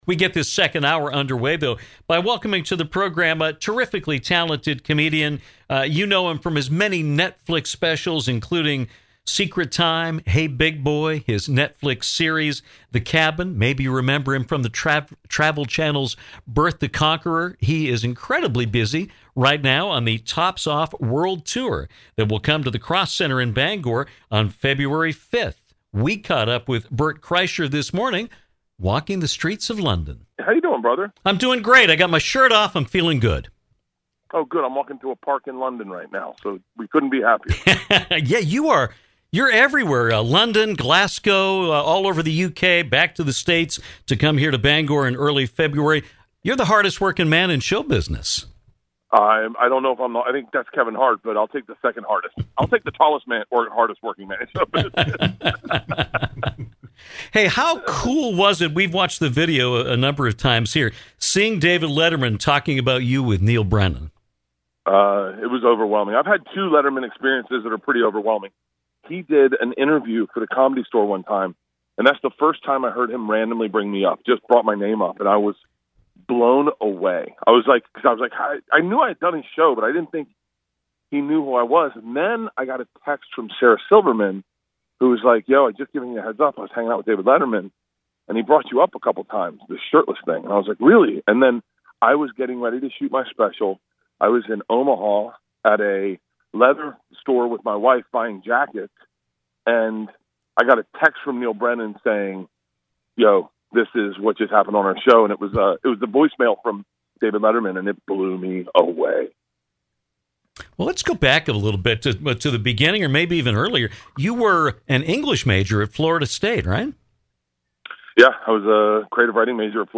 Comedian Bert Kreischer brings his Tops Off World Tour to the Cross Insurance Center in Bangor on February 5 and he joined us this afternoon to discuss the tour, his Netflix specials, his upcoming film, THE MACHINE, and more.